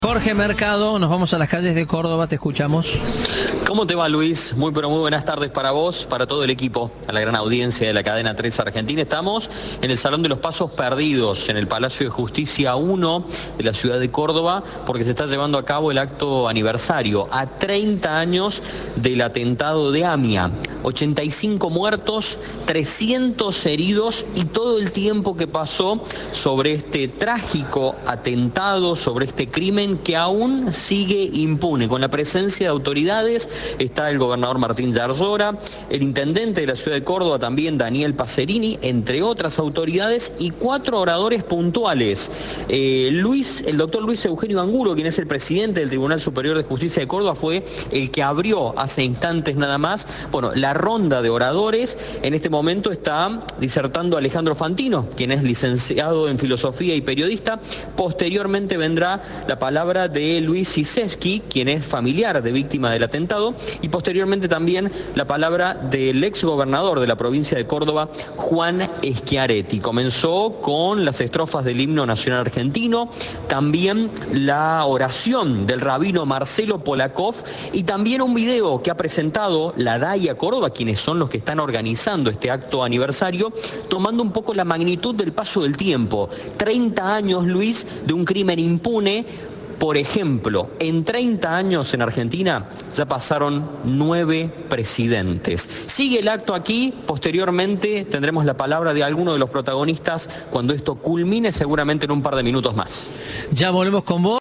Audio. Con la presencia de autoridades, se realiza en Córdoba un acto por la AMIA